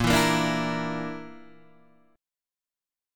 A# Major Flat 5th